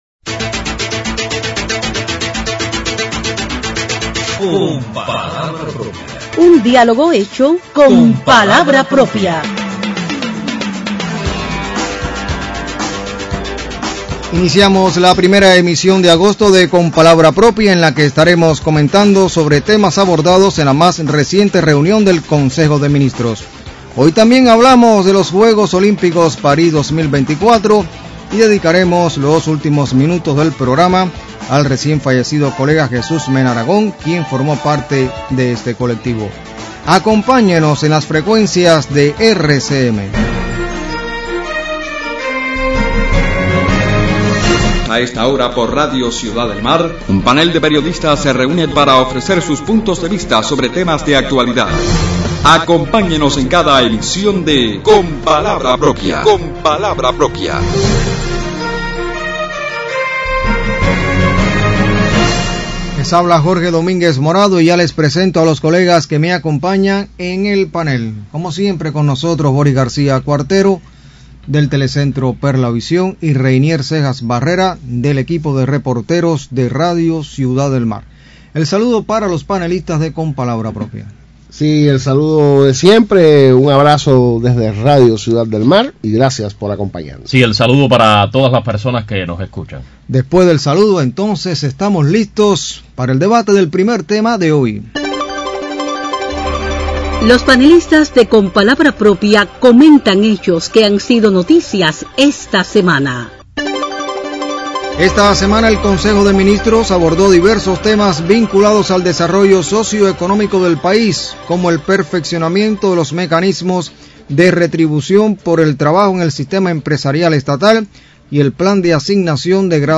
Temas analizados por el Consejo de Ministros como el perfeccionamiento del sistema empresarial estatal y la asignación de graduados a las empresas es motivo de análisis por los panelistas de Con palabra propia este sábado 3 de agosto.